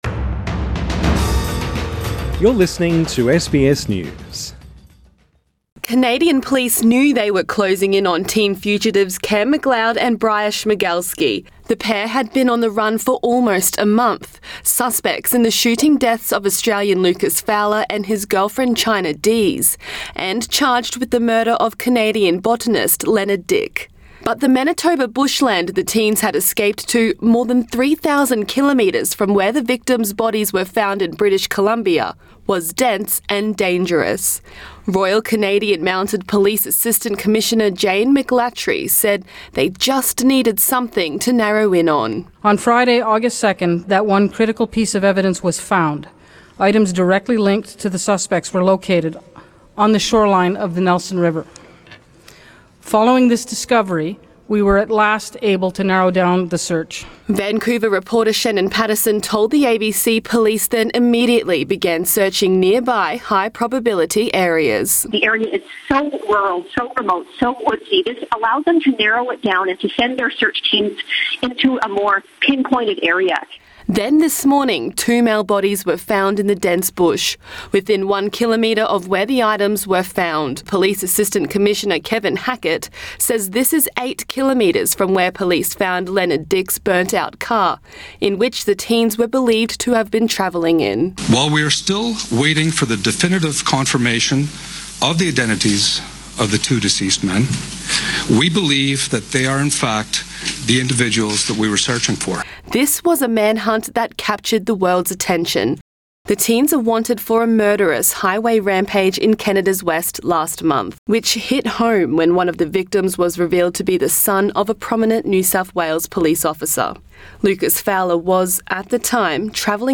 RCMP Assistant Commissioner Jane MacLatchy, speaks at a news conference in Winnipeg Source: AAP